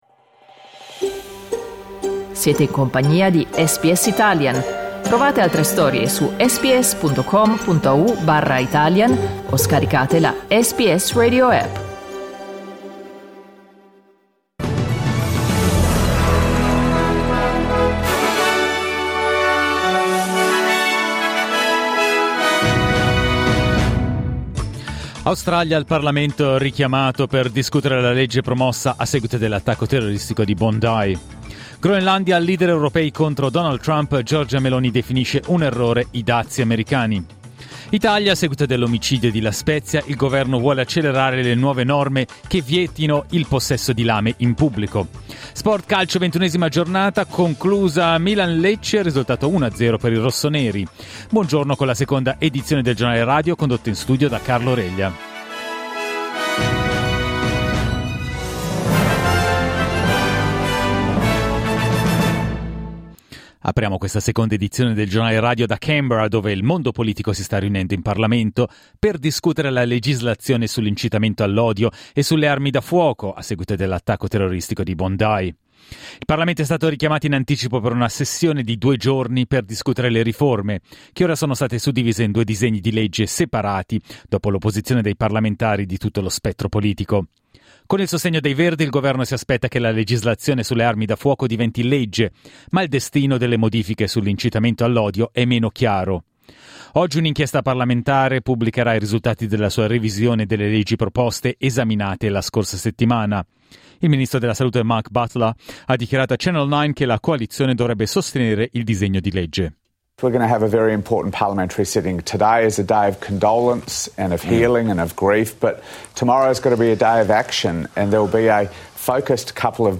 Giornale radio lunedì 19 gennaio 2026
Il notiziario di SBS in italiano.